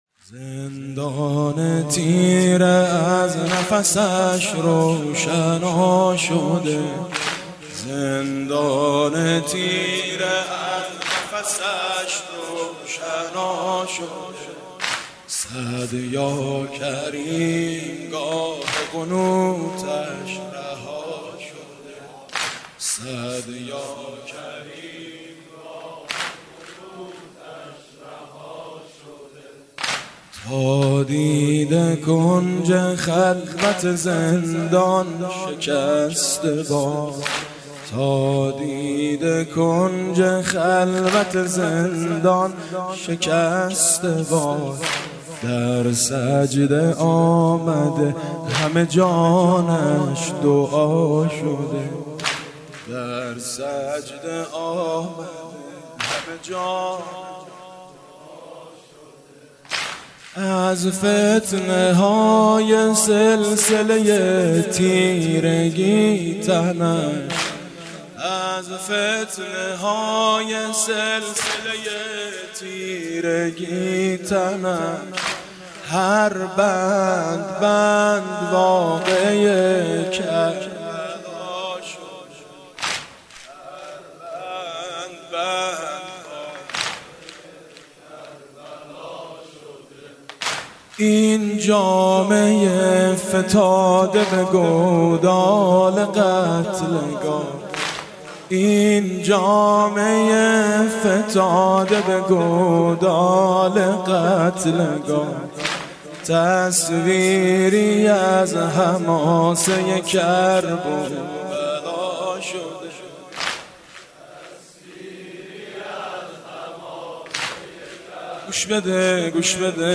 مداحی حاج میثم مطیعی به مناسبت شهادت امام موسی کاظم(ع)